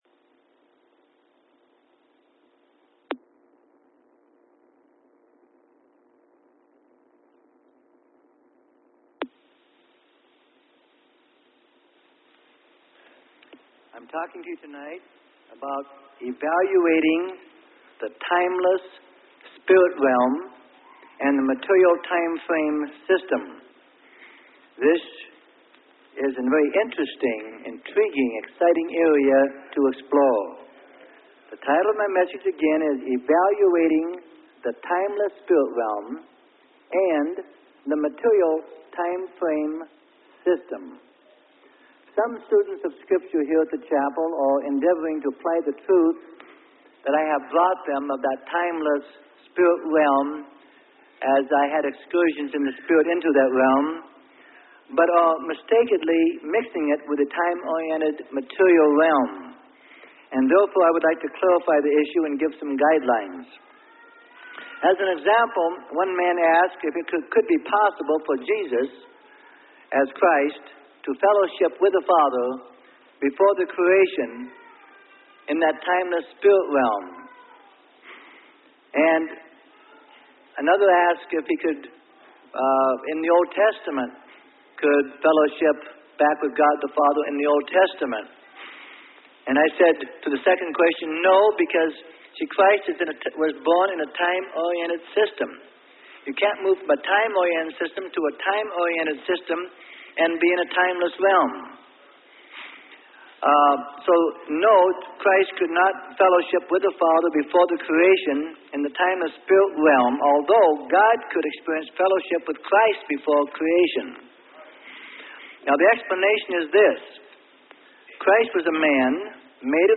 Sermon: Evaluating The Timeless Spirit Realm & The Material Time Frame System - Freely Given Online Library